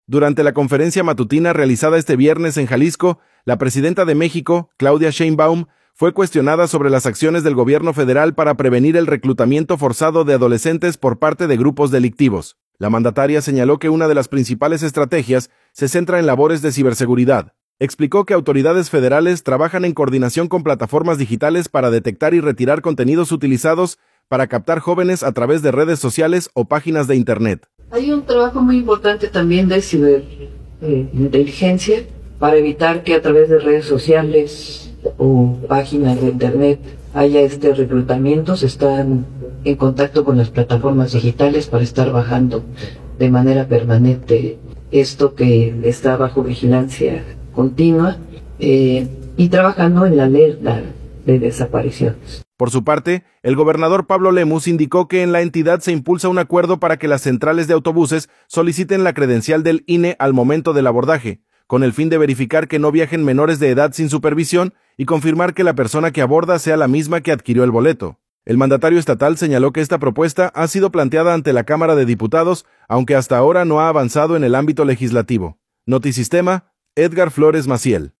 Durante la conferencia matutina realizada este viernes en Jalisco, la presidenta de México, Claudia Sheinbaum Pardo, fue cuestionada sobre las acciones del gobierno federal para prevenir el reclutamiento forzado de adolescentes por parte de grupos delictivos.